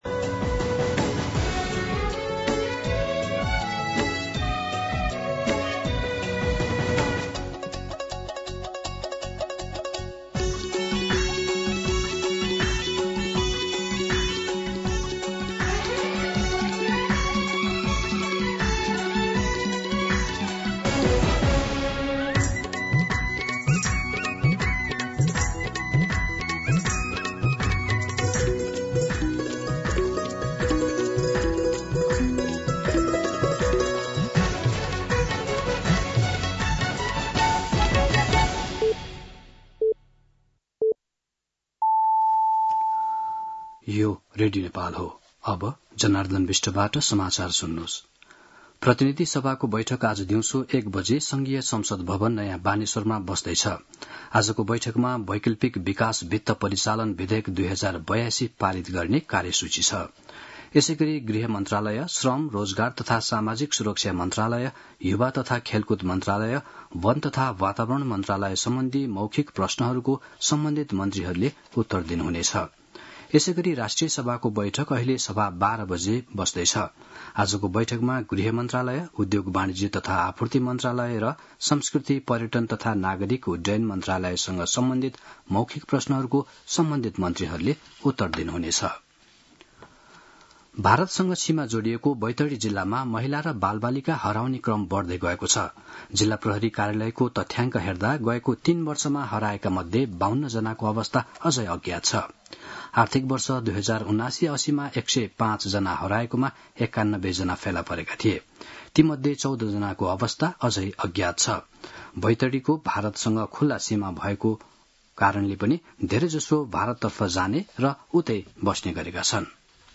मध्यान्ह १२ बजेको नेपाली समाचार : ६ भदौ , २०८२
12-pm-Nepali-News-1-2.mp3